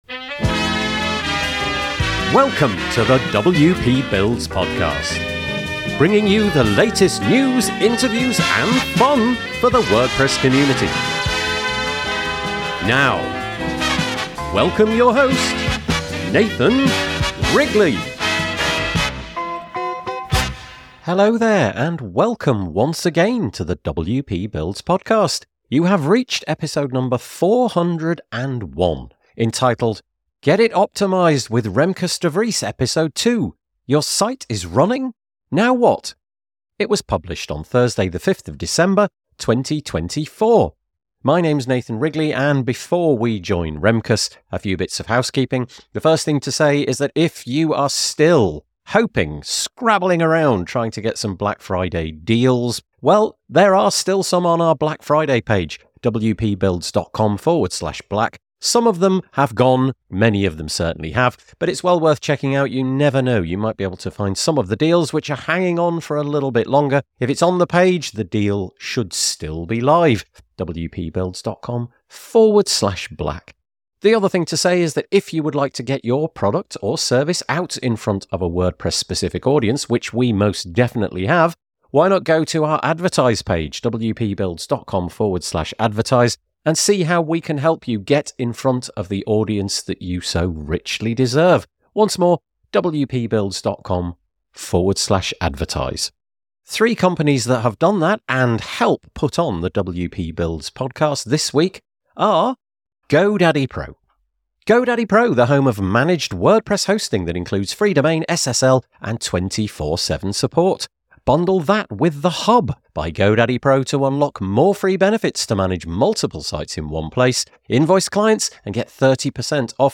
The conversation underscores that user experience and quick load times are crucial for site success.